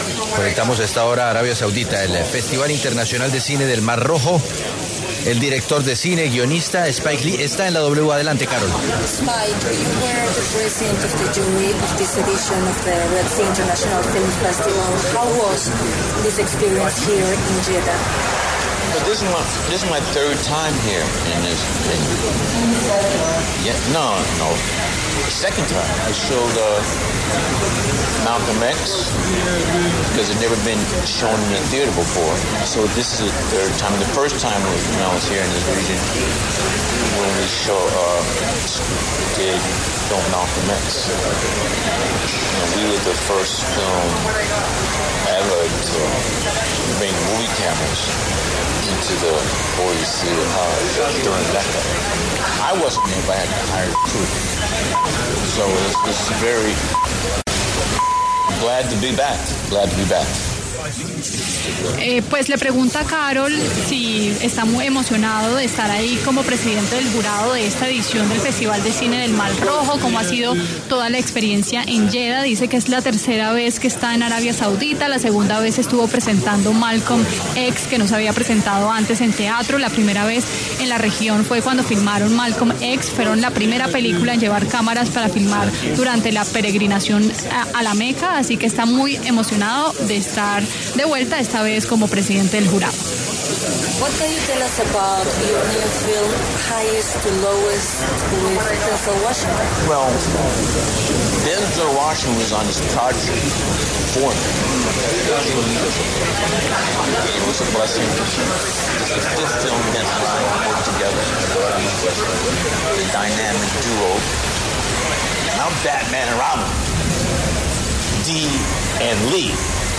Spike Lee, director de cine y guionista estadounidense, pasó por los micrófonos de La W en el marco del Festival de cine del Mar Rojo, y entregó detalles de su experiencia en Yeda.